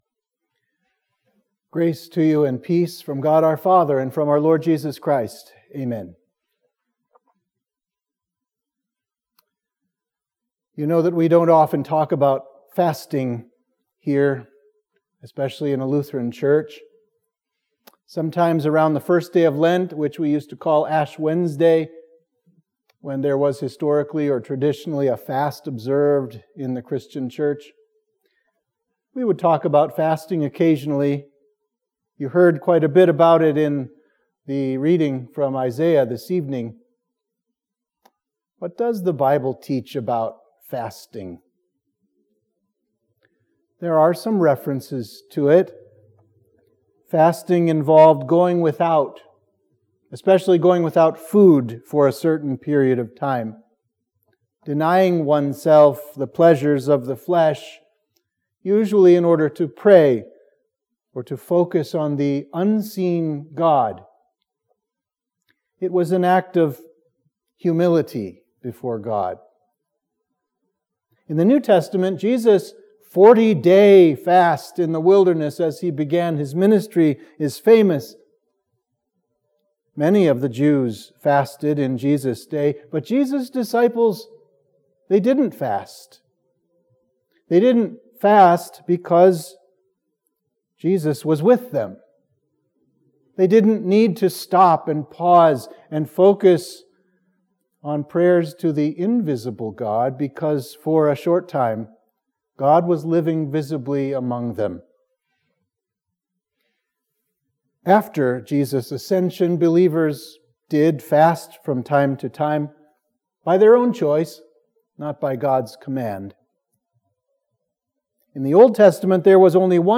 Sermon for Midweek of Trinity 16